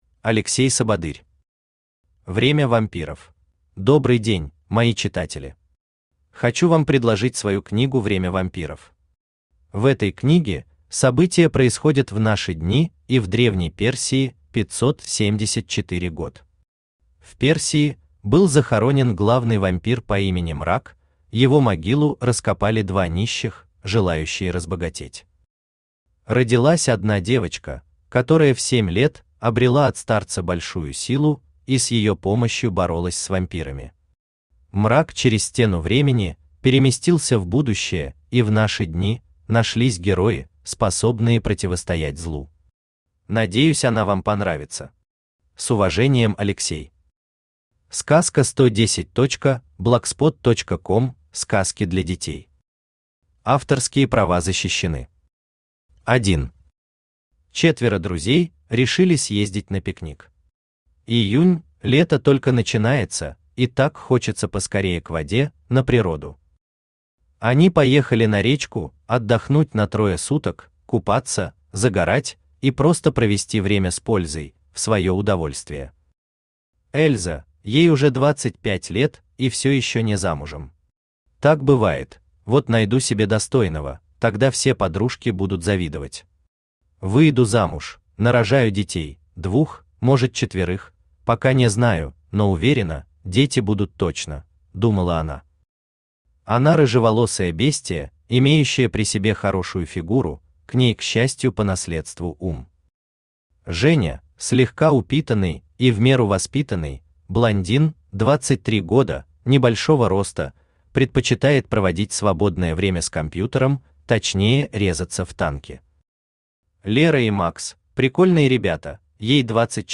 Aудиокнига Время вампиров Автор Алексей Сабадырь Читает аудиокнигу Авточтец ЛитРес. Прослушать и бесплатно скачать фрагмент аудиокниги